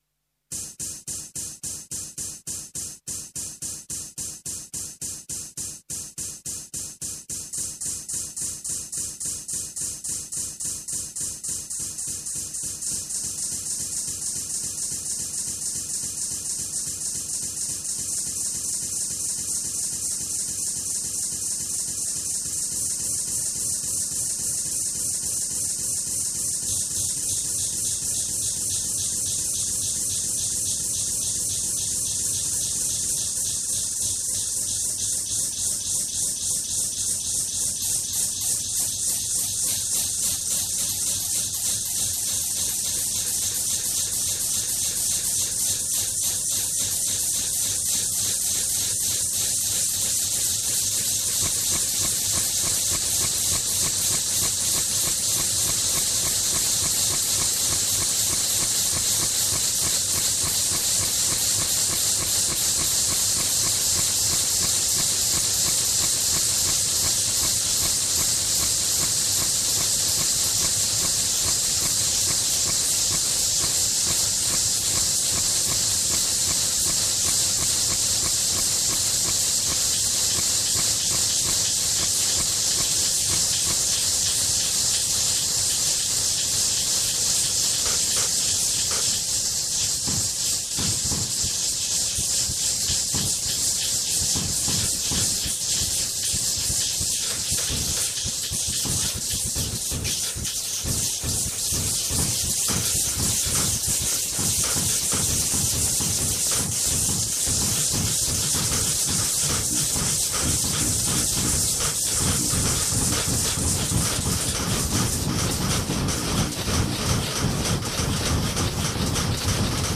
Computer- und netzbasierte Lautpoesie
abcdefghijklmnopqrstuvwxyz � audiovisuelle Lautperformance